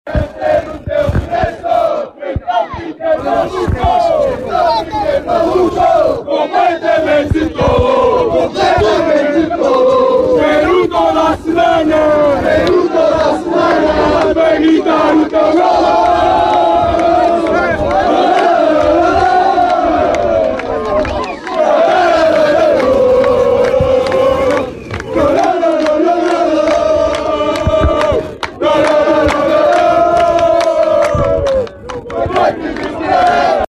Os sons da festa do Vista Alegre aos microfones da Sintonia…